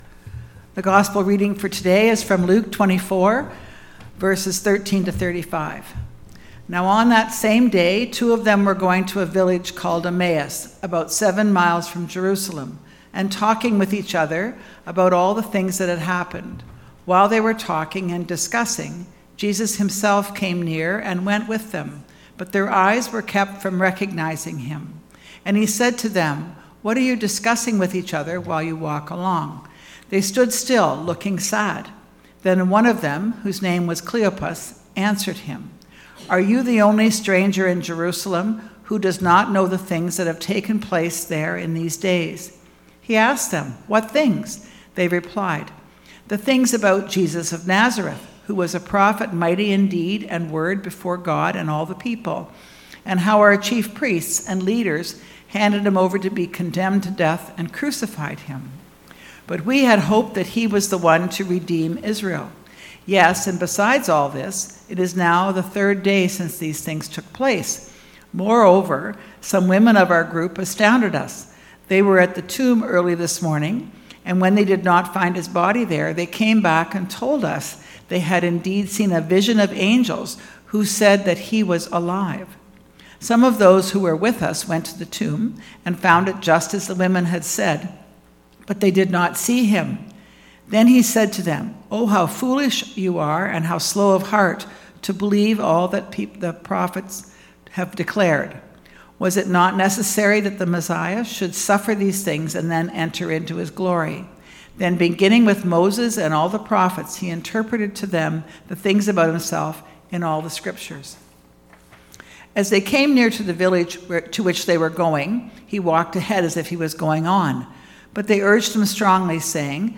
This is a shortened version of our in-person  Sunday Service.